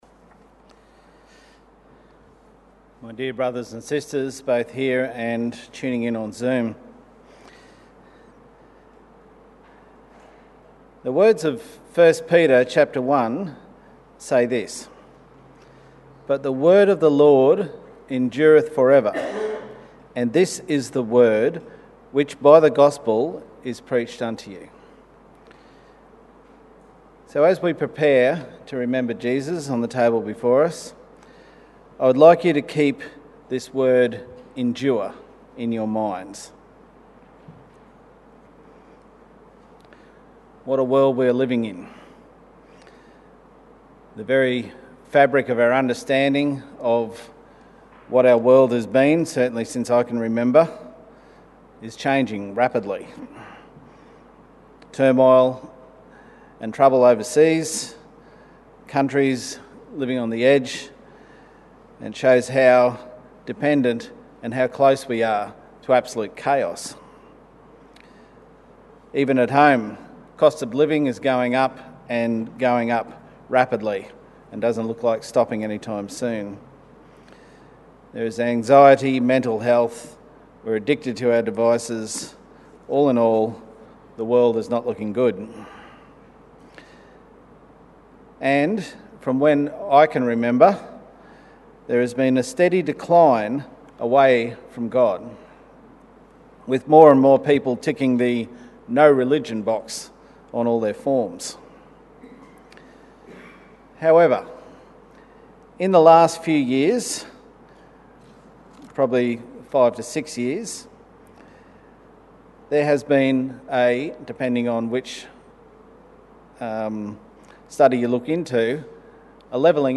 Exhortation - Endurance - Know Your Bible